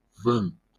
IPA/ˈvənt/